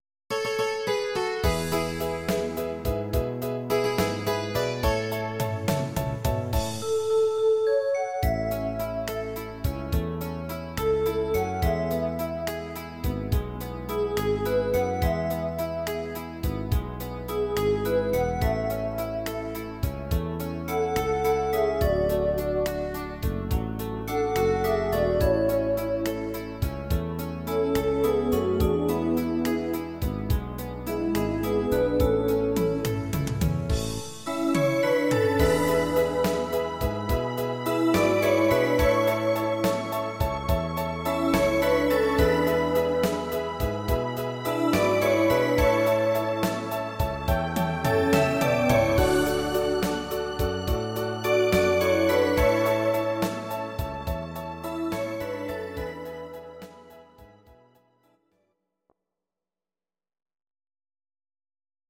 These are MP3 versions of our MIDI file catalogue.
Please note: no vocals and no karaoke included.
Your-Mix: Volkstï¿½mlich (1262)